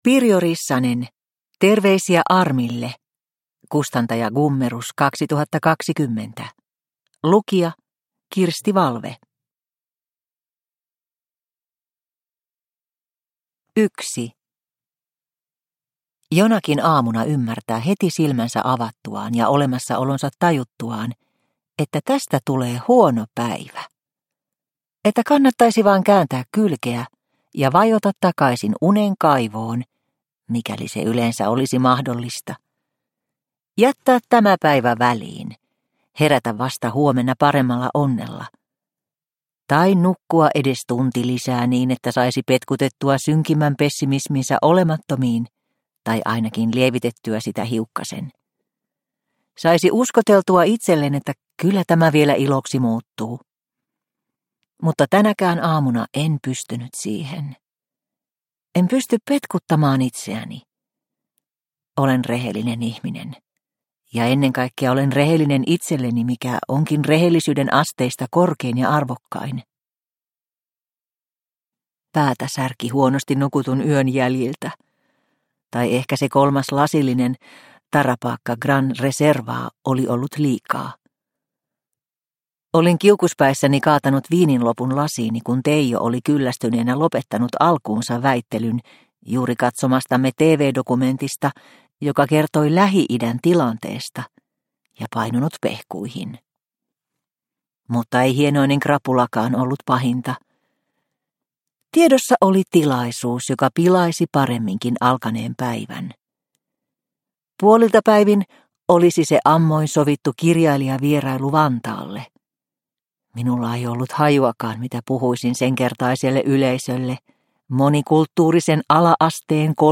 Terveisiä Armille – Ljudbok – Laddas ner